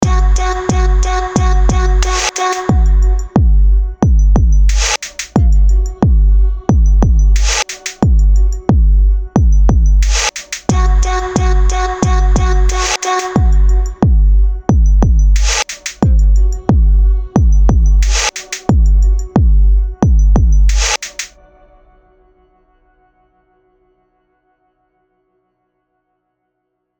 Female
English (North American)
Yng Adult (18-29), Adult (30-50)
Character / Cartoon
Female Voice Over Talent